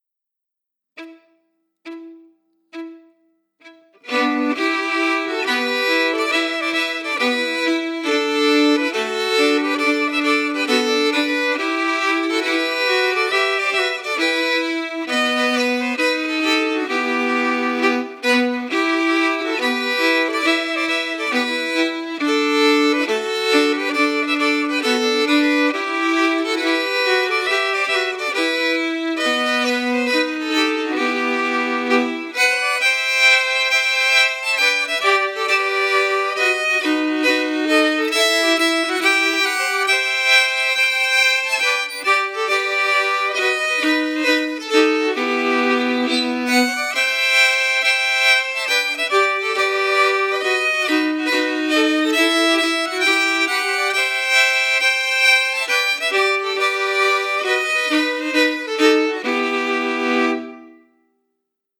Key: Em
Form: Hornpipe